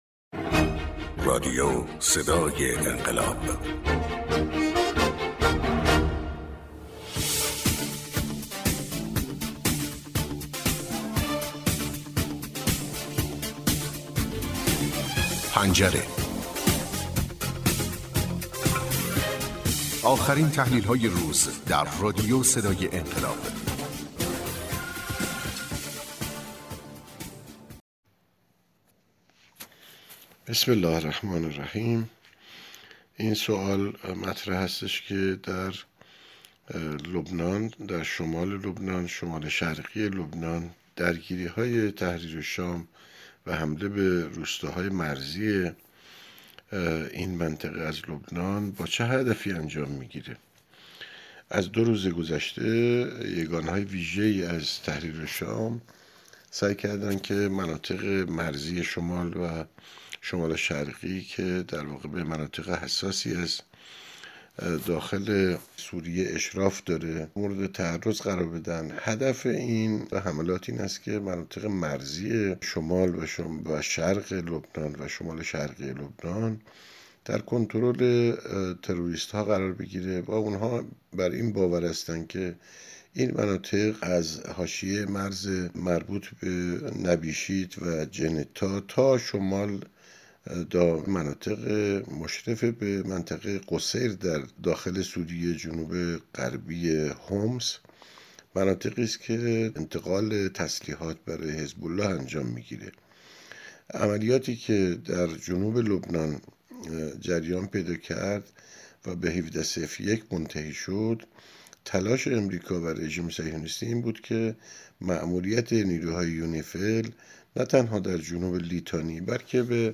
تحلیل گر سیاسی و پژوهشگر منطقه و بین الملل